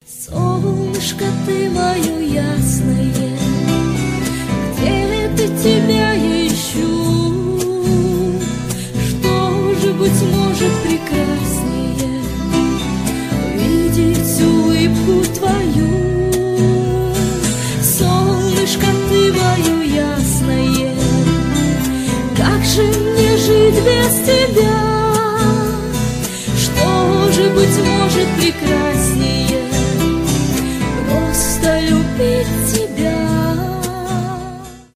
шансон
акустика , гитара